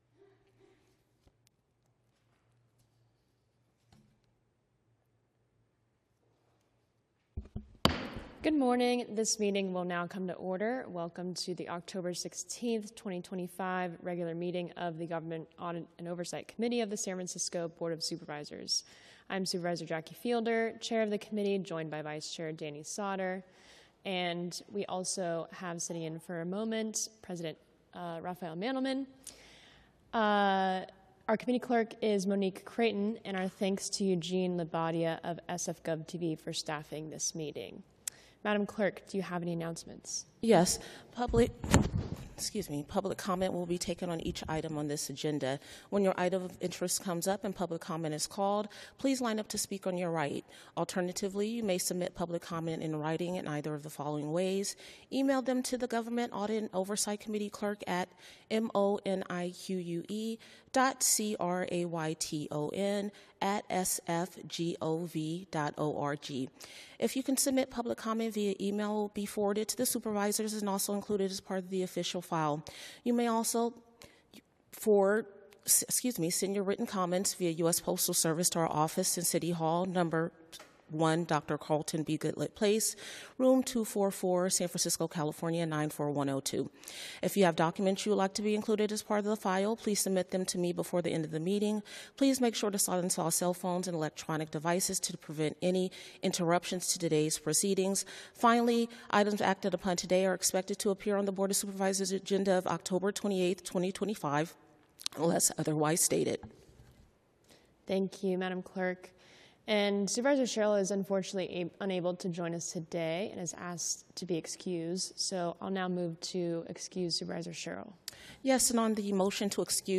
BOS - Government Audit and Oversight Committee - Regular Meeting - Oct 16, 2025